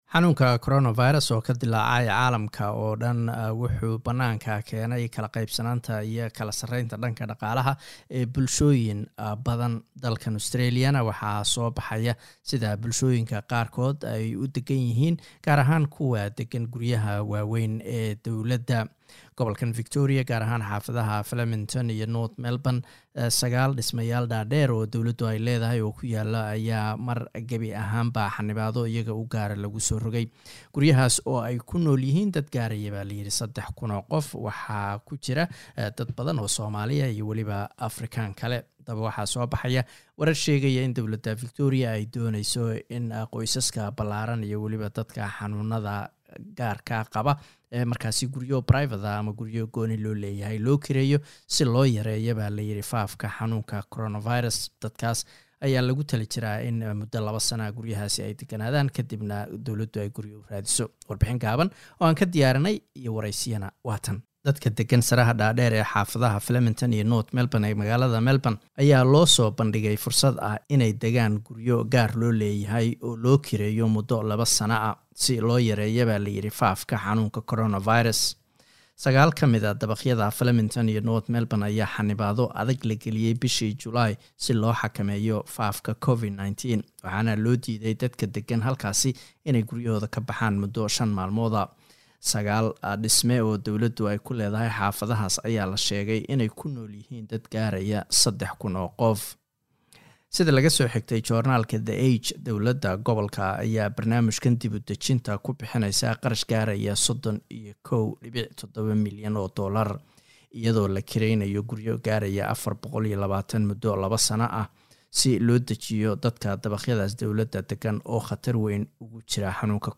Guryaha dawlada ee Flemington iyo North Melbourne ayay dawlada Victoria doonaysa inay dadka deggan qaarkood u kirayso guryo kale. Haddaba ma yeelayaan dadkaasi? waraysi ayaan la yeelanay qaar ka mid ah dadka deggan.